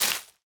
Zniszczono blok Blocks Gra gdy zniszczono blok block.leaf_litter.break subtitles.block.generic.break 1.0 0.8 16
Leaf_litter_break1.ogg